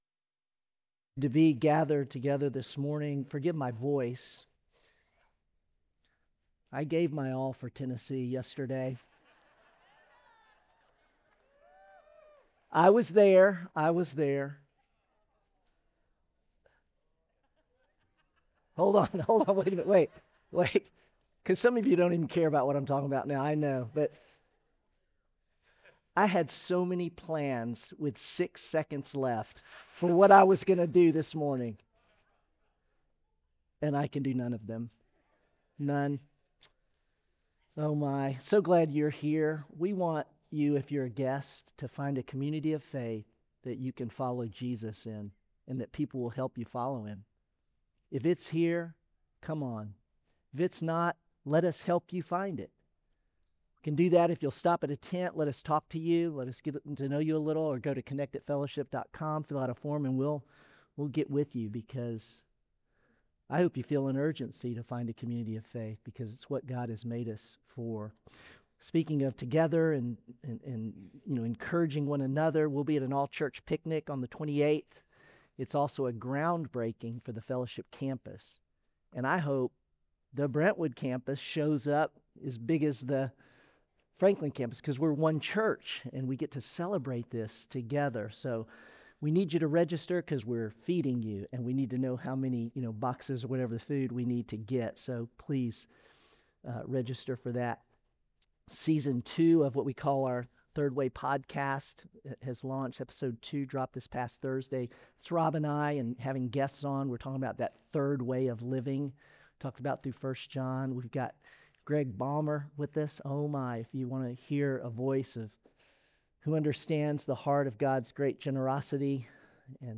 Sermon Unshakeable: Romans 8